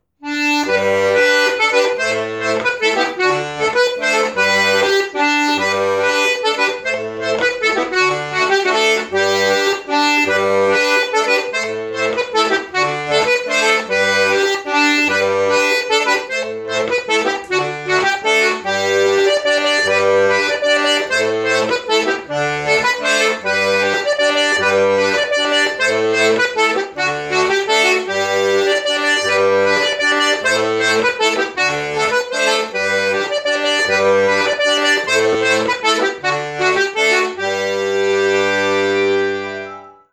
Mazurka du zèbre/ Musique ensemble 2019-2020/ Cancoillottefolk/2020